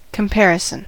comparison: Wikimedia Commons US English Pronunciations
En-us-comparison.WAV